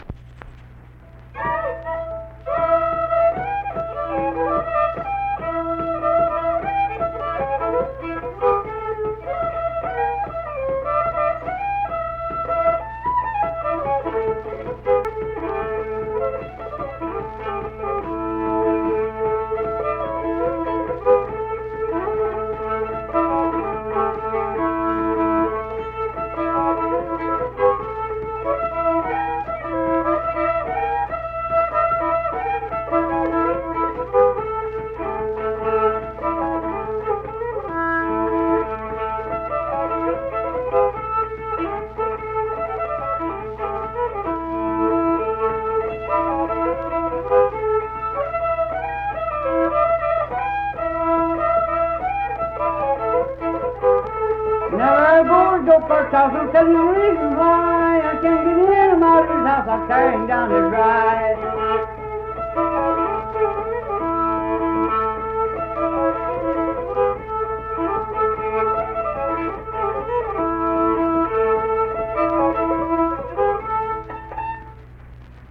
Unaccompanied fiddle music
Instrumental Music
Fiddle
Mingo County (W. Va.), Kirk (W. Va.)